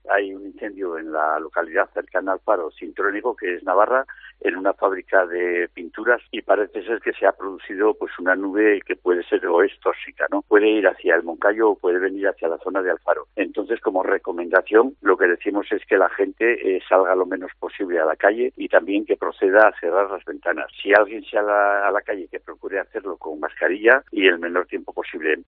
Ela alcalde en funciones del municipio ha pedido a sus vecinos en COPE que no salgan a la calle y que mantengan las puertas y ventanas cerradas de sus domicilios, ante la toxicidad de la nube que se va a cercando hacia el municipio.